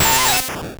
Cri d'Aspicot dans Pokémon Or et Argent.